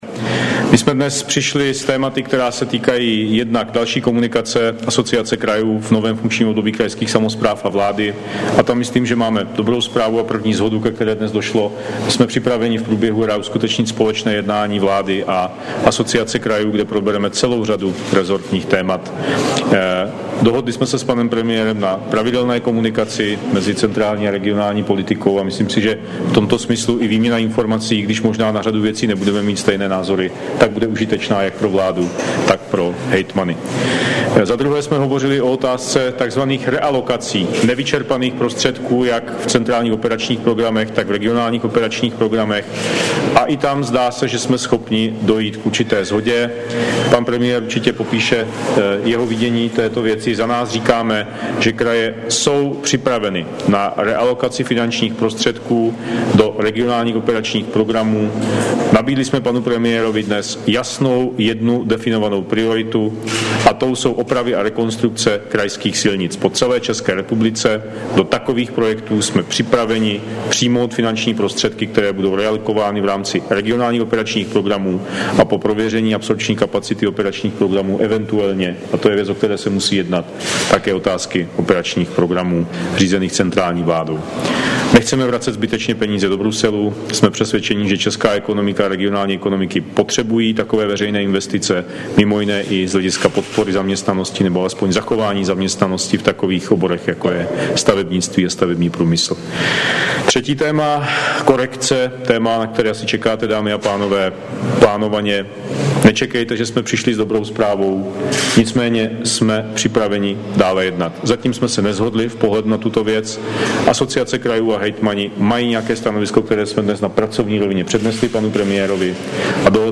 Zvuková příloha Brífink po jednání premiéra s hejtmany v Jihlavě, 21. března 2013 MP3 • 13225 kB